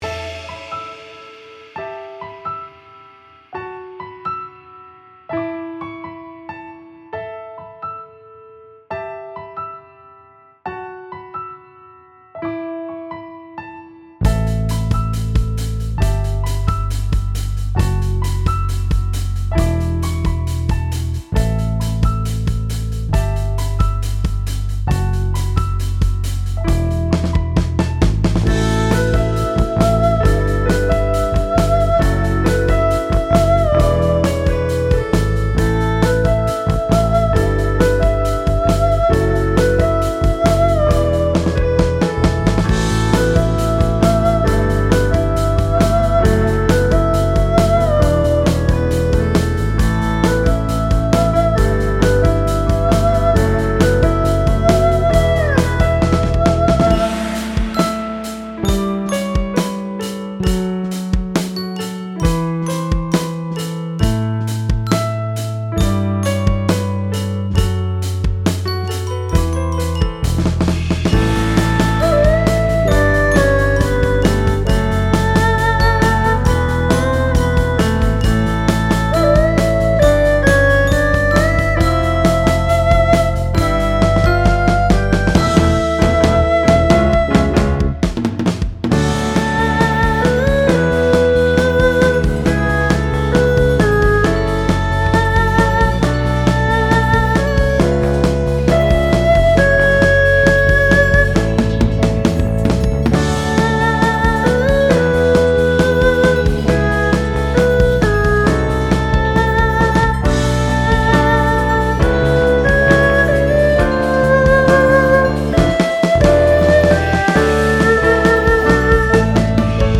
中国の伝統楽器(二胡)をメインメロディに据えた楽曲です。
最初に冒頭のピアノのフレーズが思いつき、そこから曲を組み立てていきました。
曲のイメージとしては、感動的、エモーショナルといった雰囲気があると思っています。